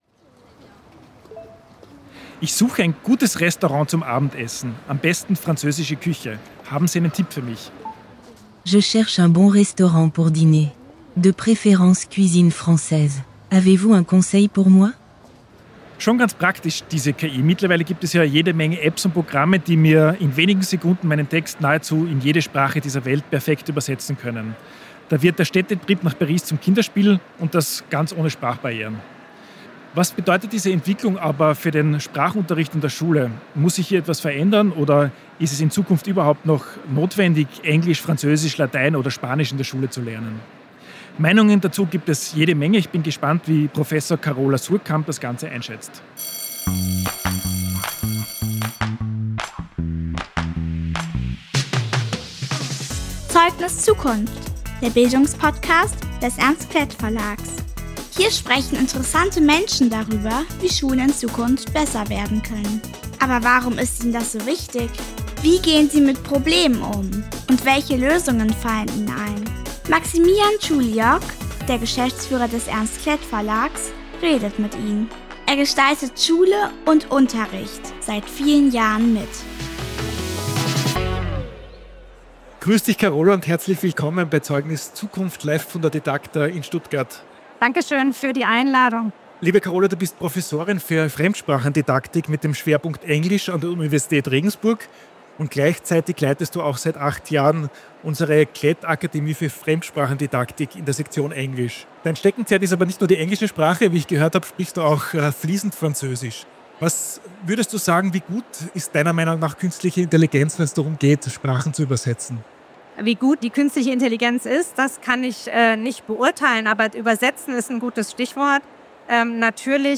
im Live-Talk auf der didacta diskutiert.